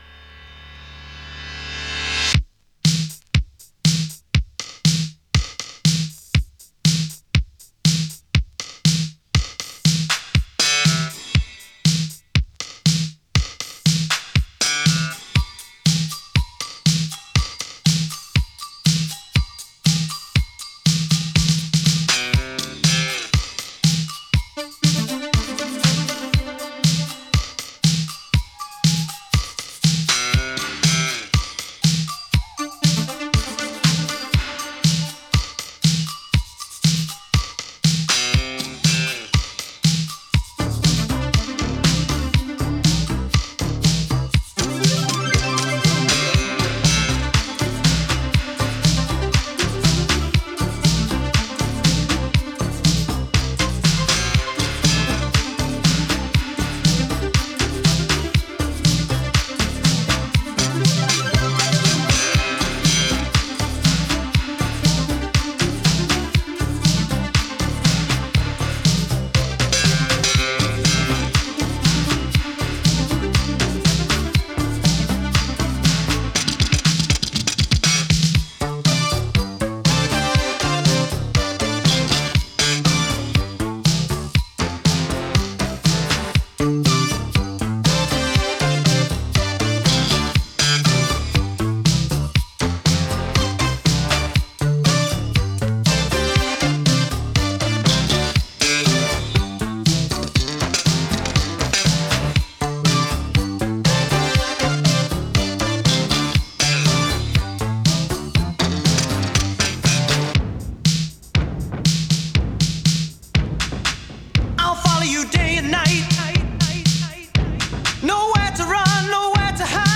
胸騒ぎを掻き立てるアーバン・エレクトロ・ファンク！